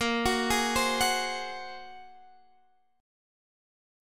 A#7sus2#5 Chord